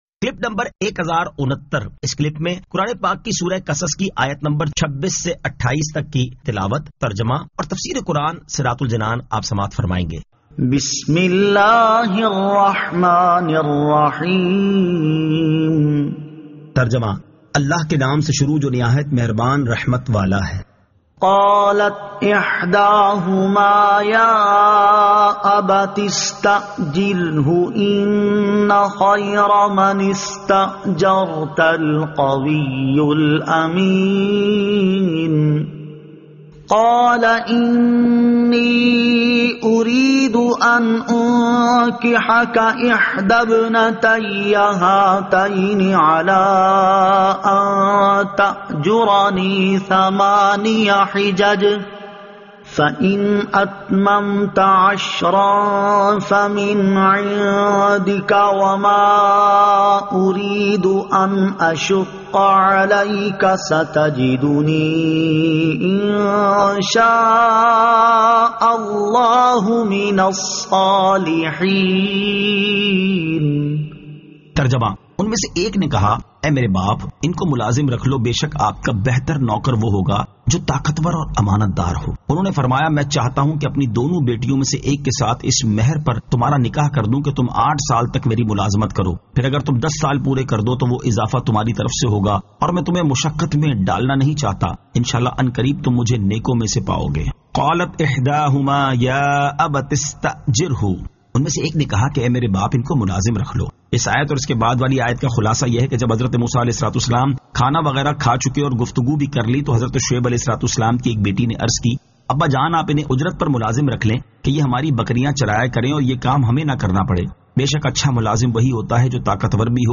Surah Al-Qasas 26 To 28 Tilawat , Tarjama , Tafseer